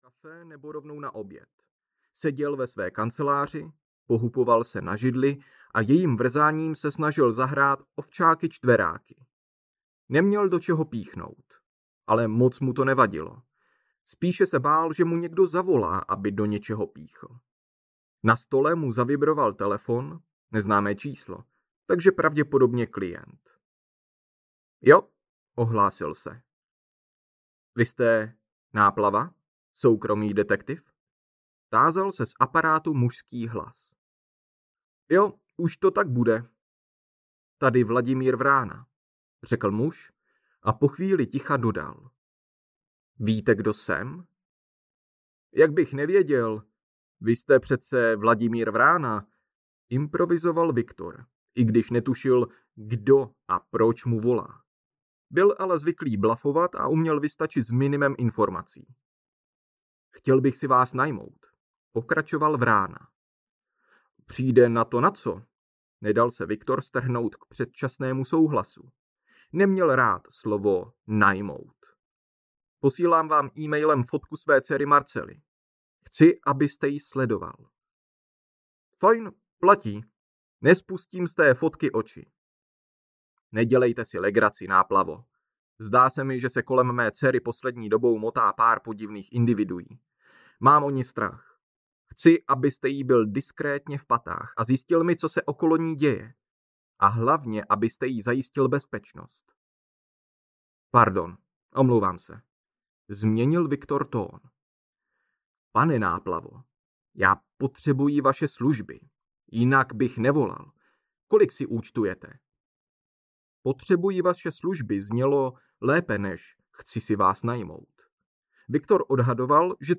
Podzimní boogie-woogie audiokniha
Ukázka z knihy